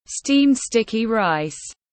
Xôi tiếng anh gọi là steamed sticky rice, phiên âm tiếng anh đọc là /stiːmd ˈstɪk.i raɪs/
Steamed sticky rice /stiːmd ˈstɪk.i raɪs/